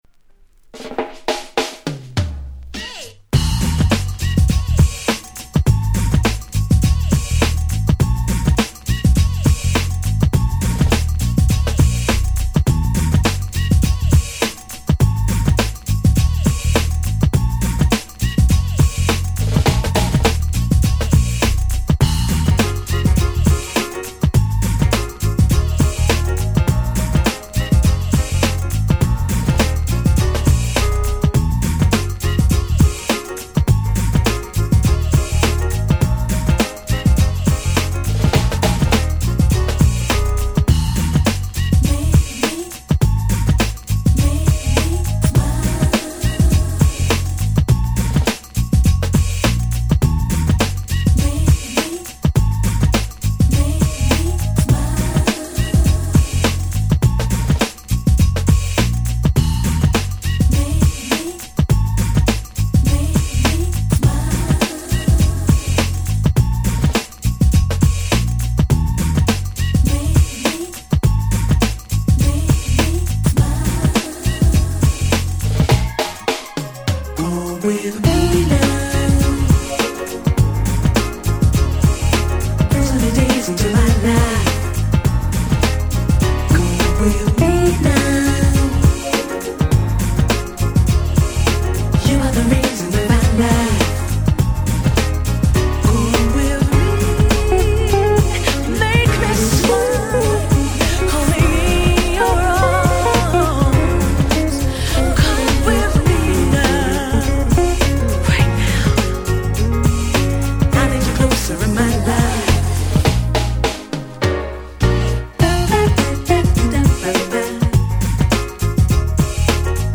93' Nice Acid Jazz LP !!